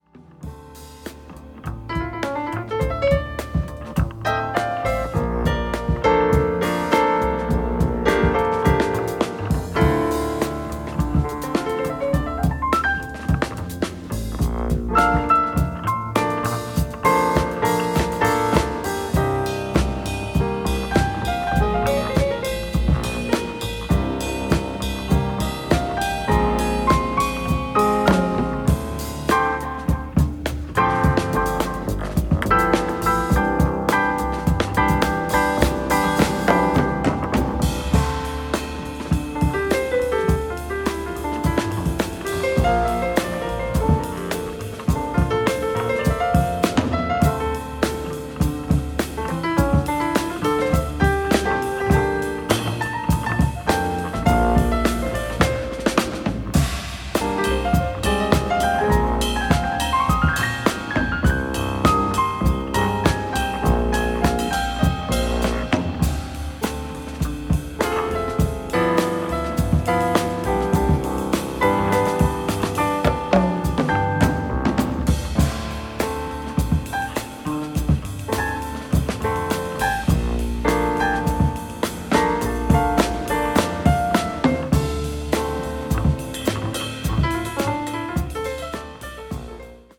これぞ日本のジャズと唸りたくなるメランコリックな